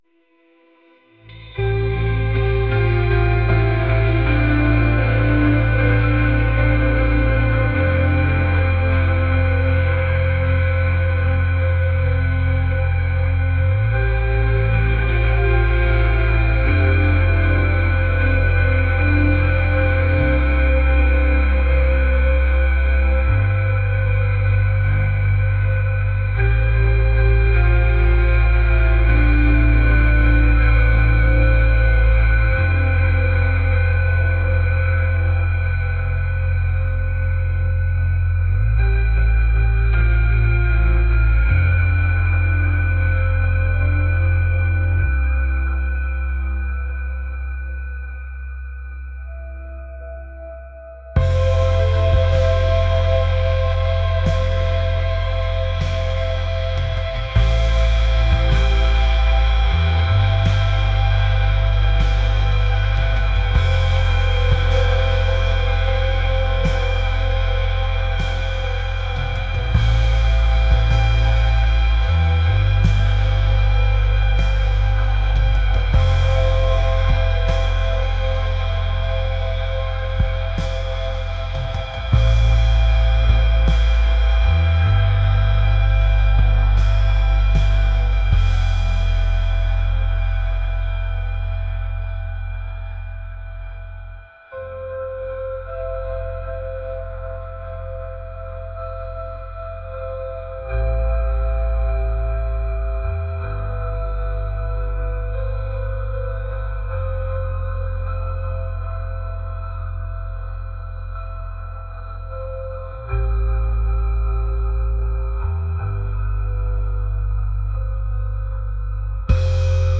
atmospheric | dreamy